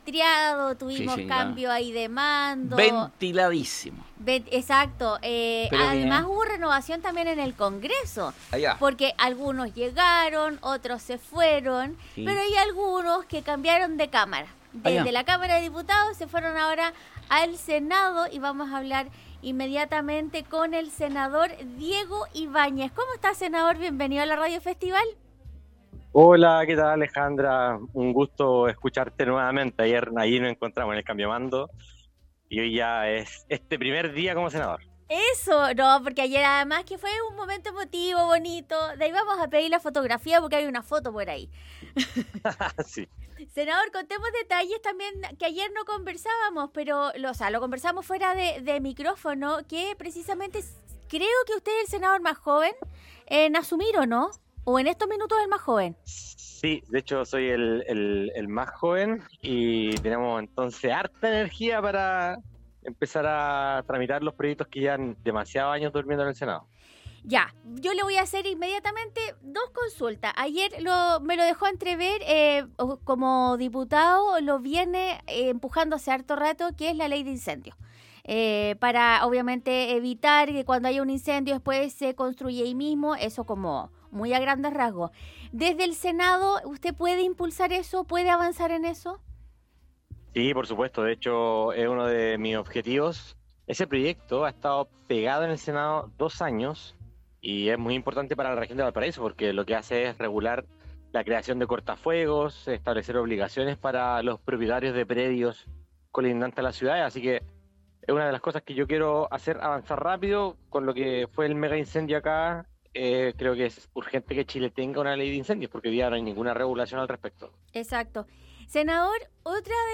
El recién asumido senador de la Región de Valparaíso, Diego Ibañez, conversó con Radio Festival para contar sobre los proyectos que comenzará a impulsar desde la próxima semana.